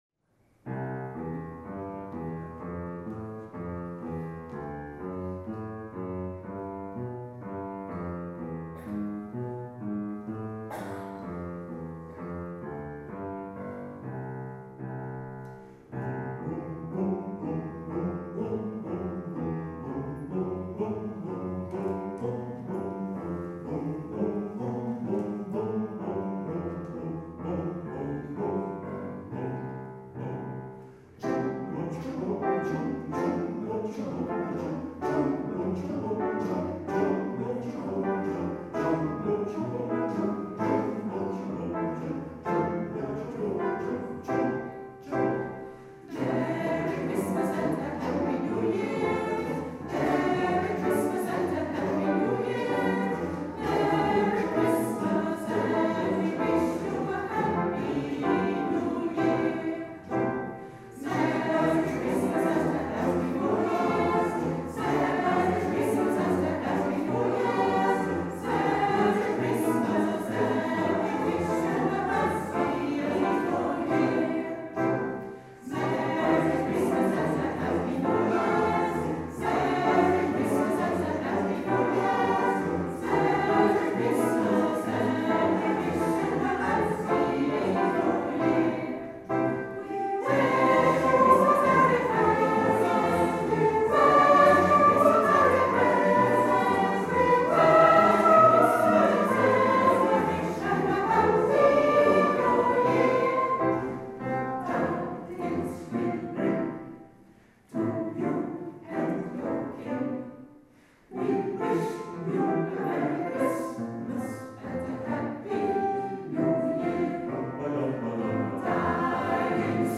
Kerstconcert PVT Salvenbos Heverlee
Piano
Dwarsfluit